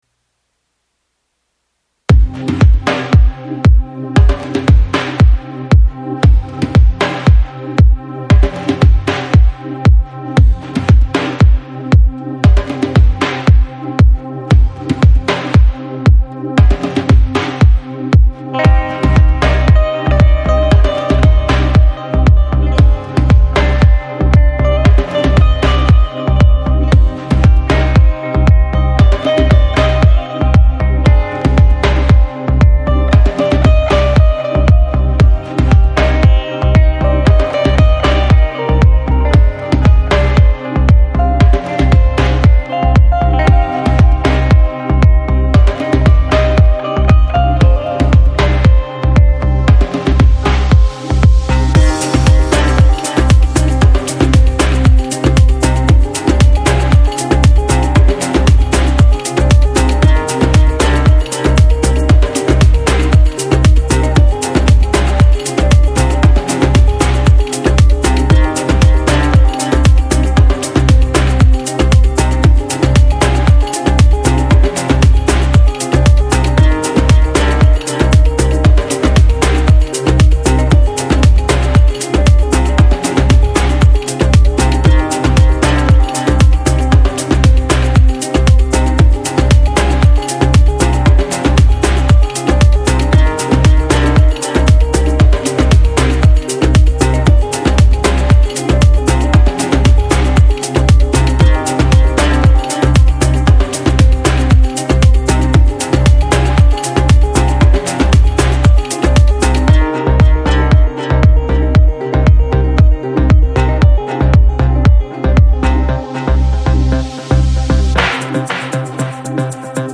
Chill Out, Downtempo, Organic House, Ethnic, World